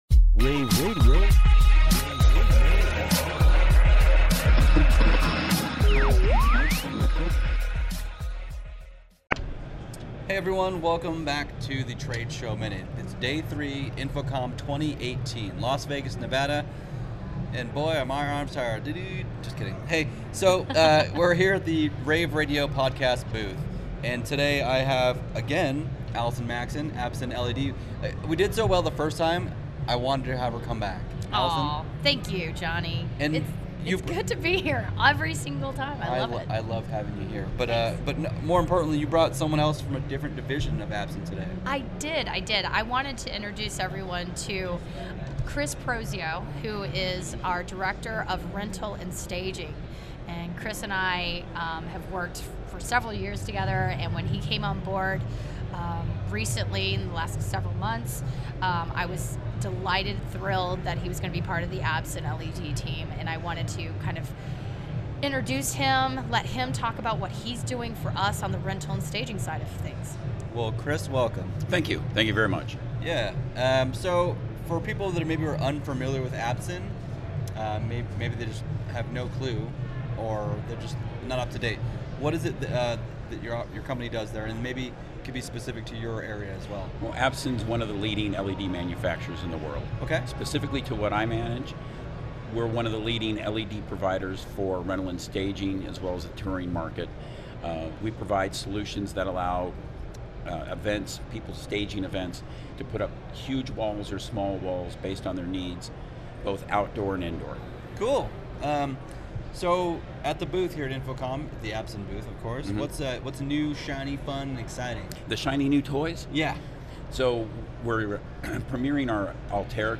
interviews
at InfoComm 2018.